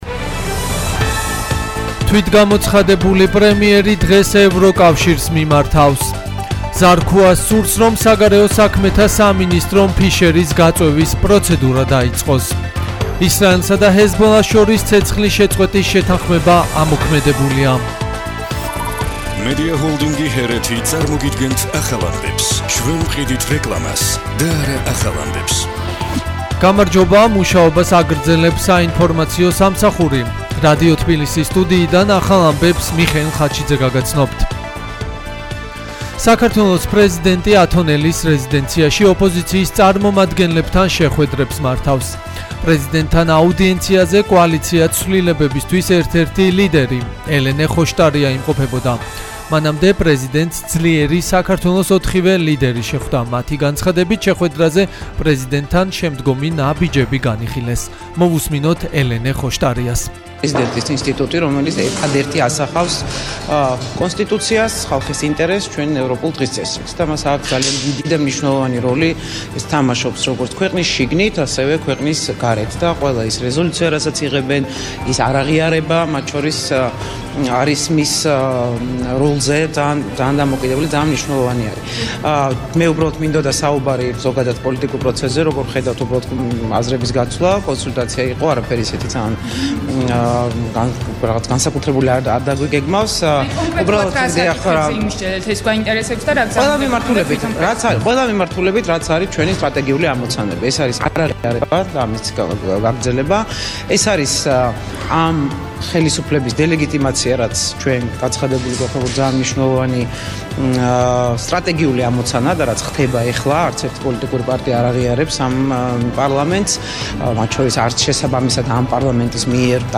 ახალი ამბები 17:00 საათზე